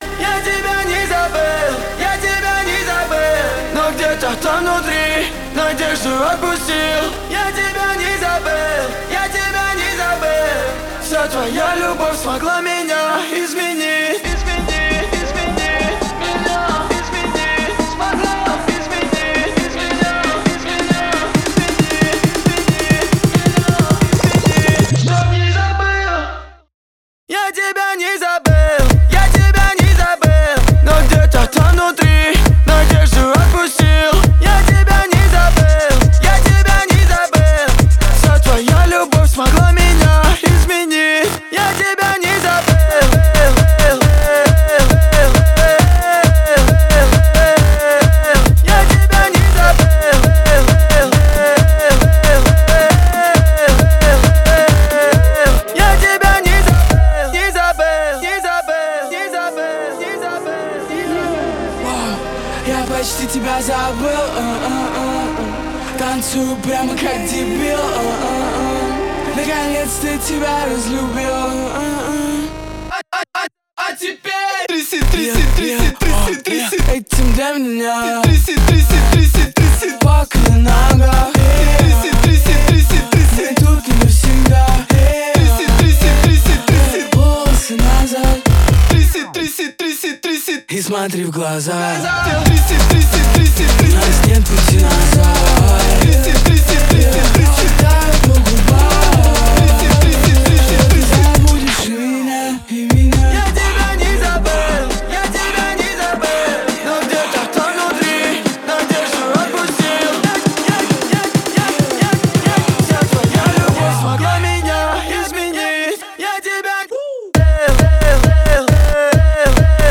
новый совместный трек с элементами электронной музыки